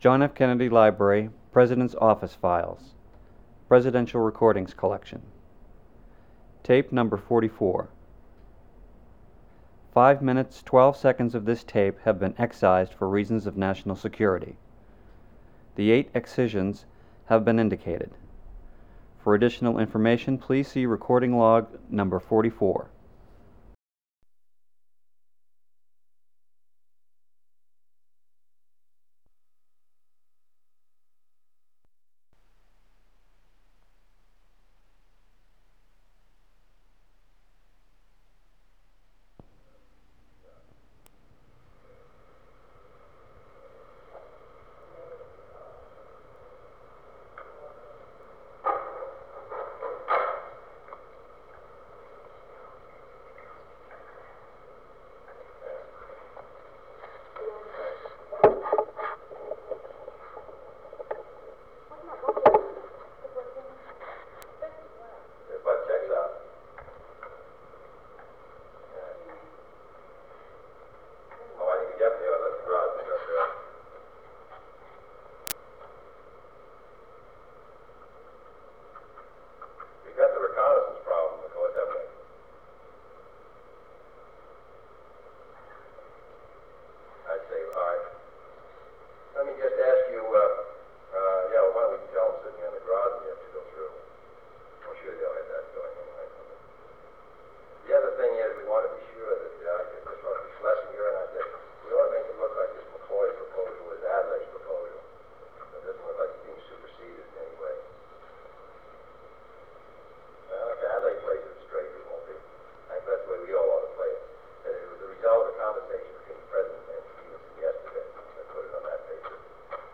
Informal Office Conversations
Secret White House Tapes | John F. Kennedy Presidency Informal Office Conversations Rewind 10 seconds Play/Pause Fast-forward 10 seconds 0:00 Download audio Previous Meetings: Tape 121/A57.